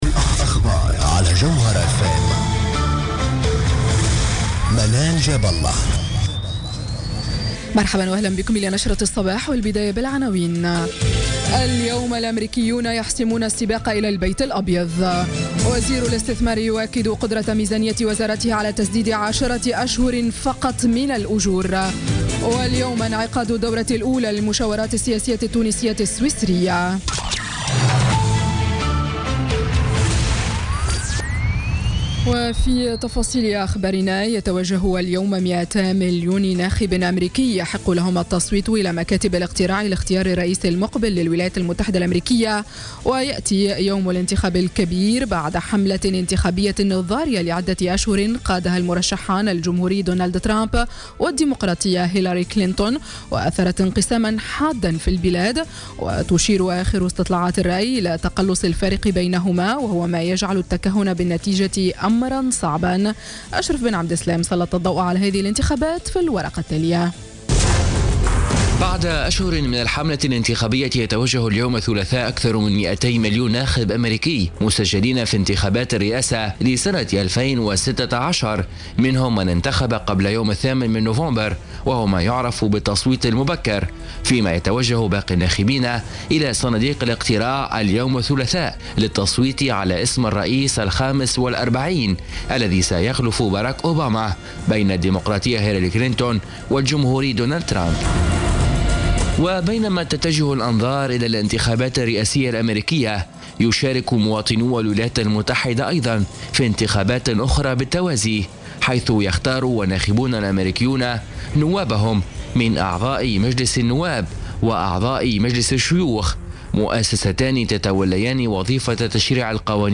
نشرة أخبار السابعة صباحا ليوم الثلاثاء 8 نوفمبر 2016